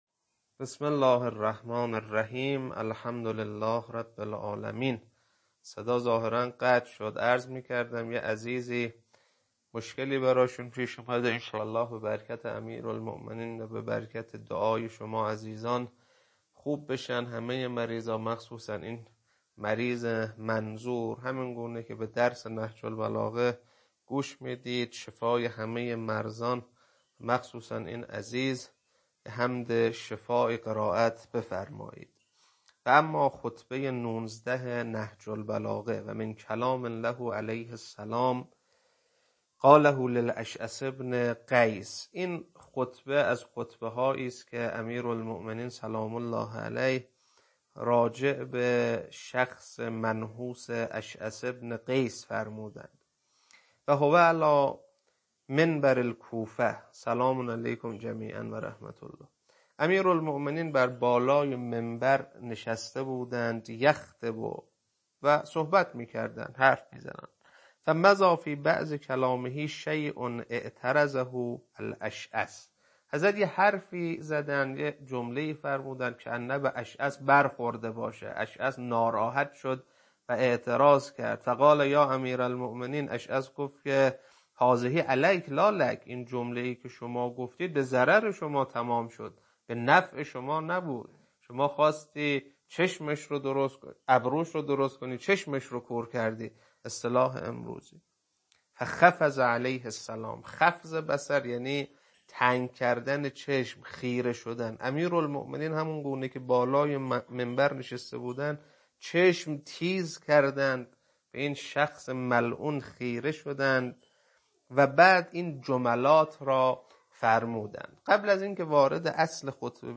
خطبه 19.mp3